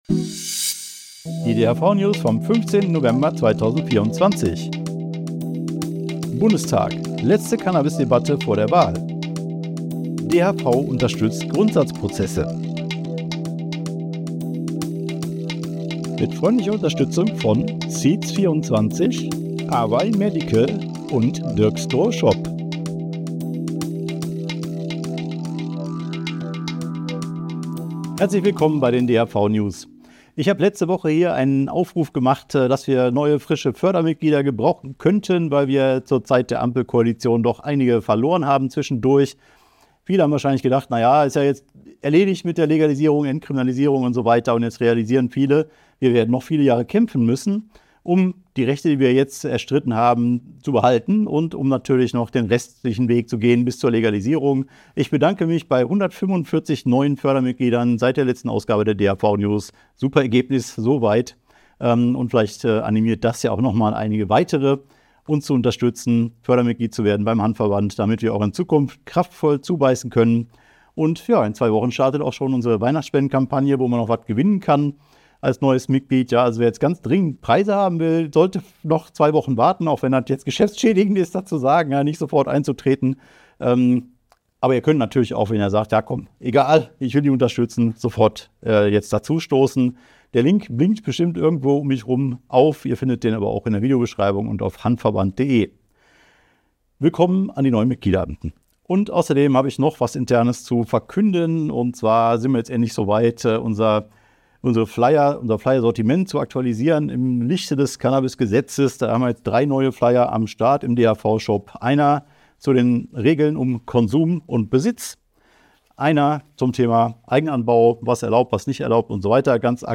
DHV-News # 444 Die Hanfverband-Videonews vom 15.11.2024 Die Tonspur der Sendung steht als Audio-Podcast am Ende dieser Nachricht zum downloaden oder direkt hören zur Verfügung.